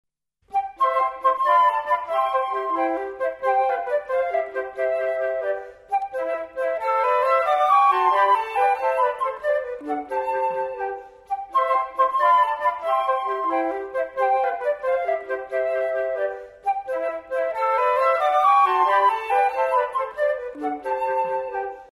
für 3 Flöten